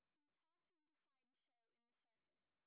sp19_street_snr30.wav